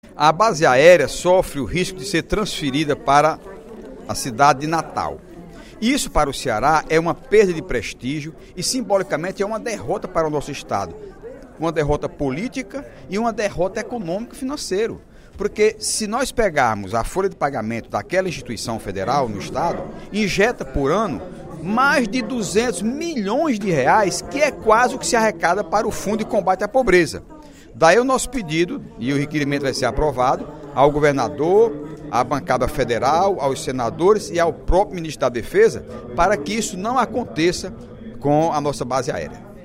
O deputado Heitor Férrer (PDT) informou, durante o primeiro expediente da sessão plenária da Assembleia Legislativa desta quarta-feira (13/11), que apresentou requerimento, a ser encaminhado ao Ministério da Defesa, à bancada federal do Ceará e ao governador Cid Gomes, em favor da manutenção da Base Aérea de Fortaleza, que está ameaçada de ser transferida para o Rio Grande do Norte.